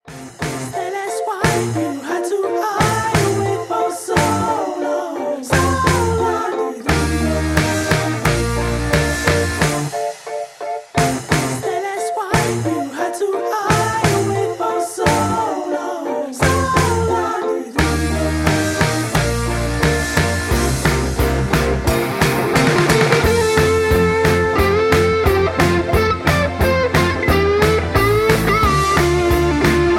Backing track Karaoke
Pop, Rock, Disco, 1970s